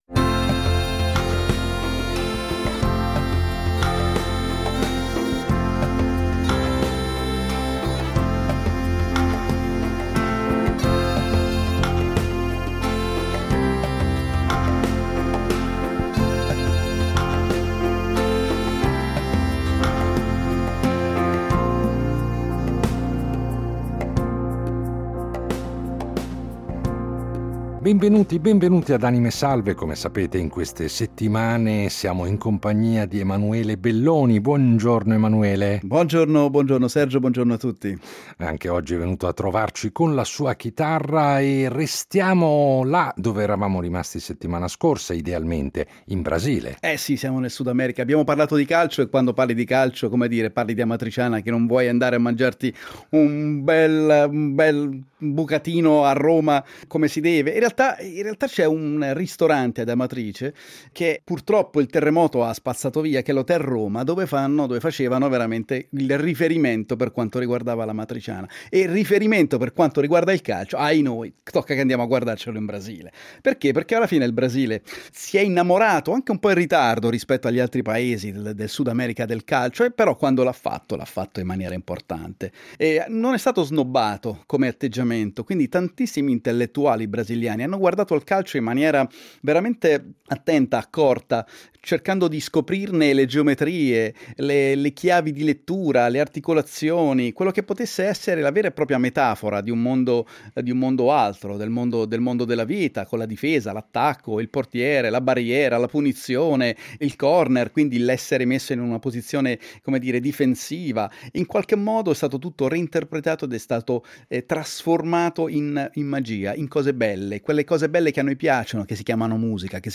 Il nostro ospite è un cantautore colto e raffinato che vale davvero la pena di scoprire.